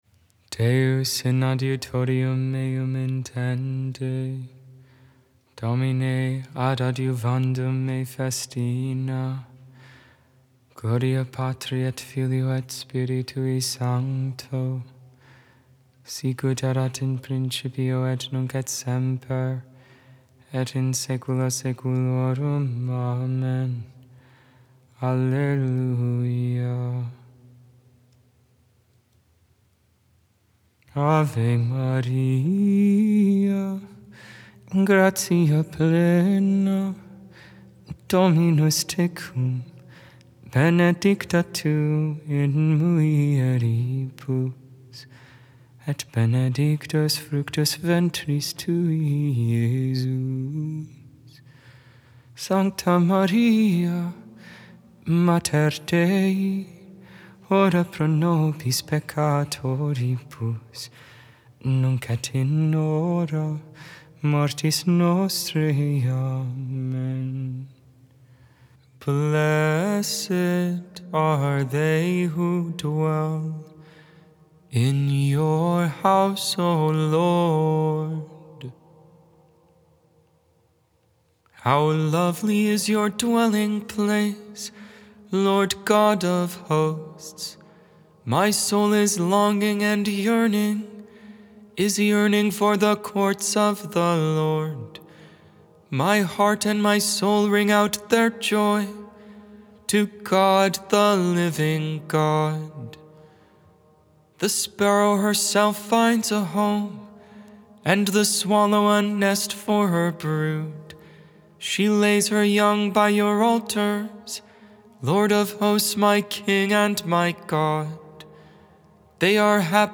Gregorian